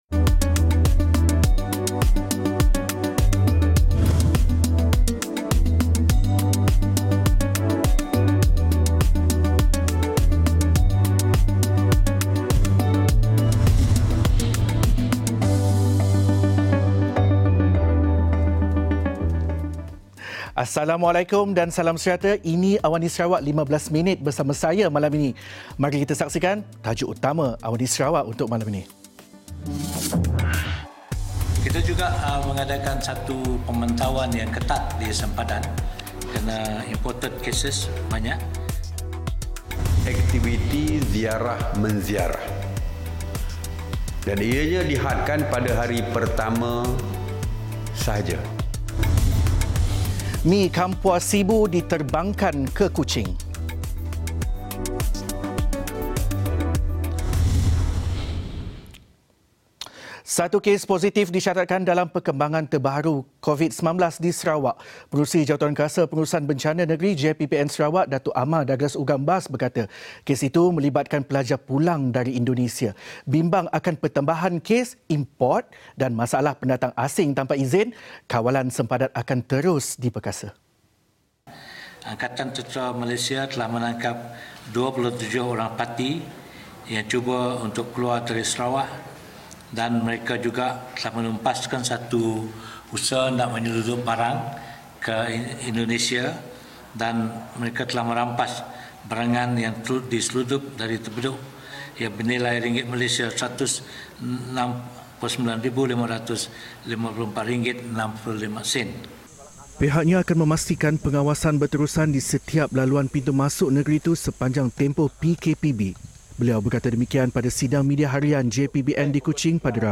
Laporan berita padat dan ringkas dari Bumi Kenyalang hari ini